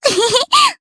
May-Vox-Laugh_jp.wav